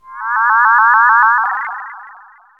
SI2 BEES 07R.wav